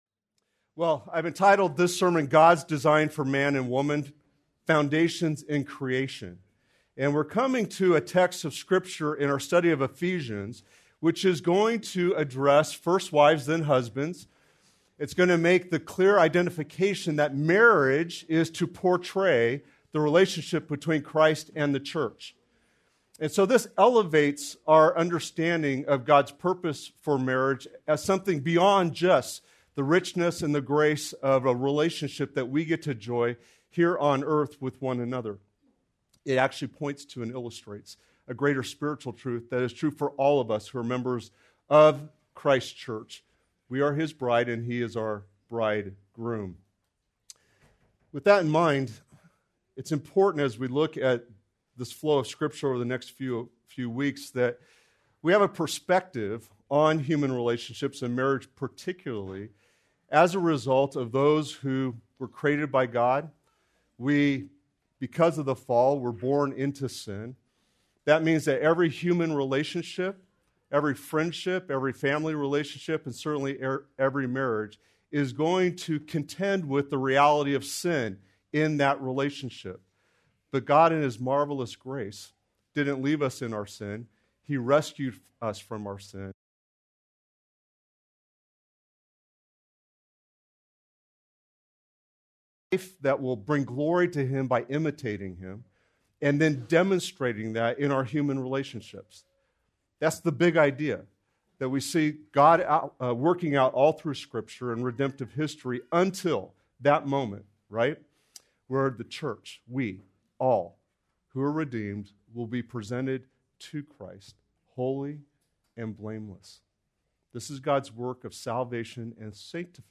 Please note that, due to technical difficulties, this recording skips brief portions of audio.